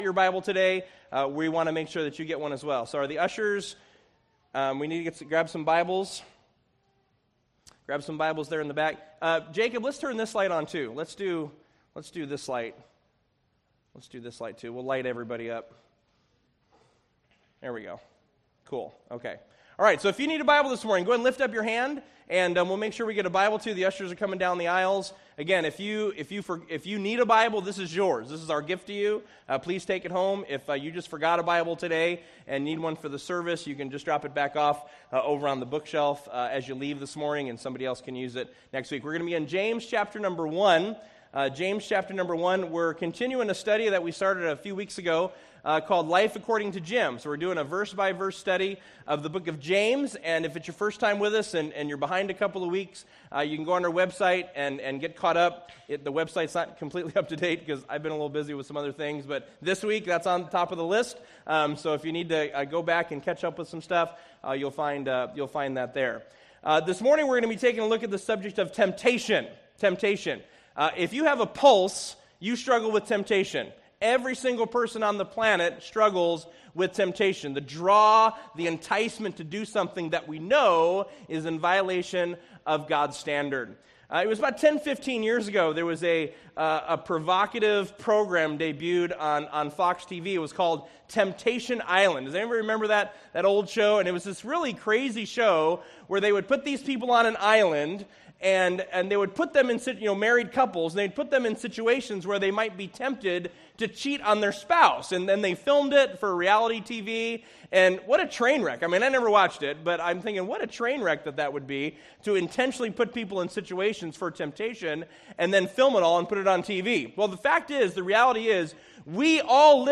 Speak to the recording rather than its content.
Service Type: Weekend Services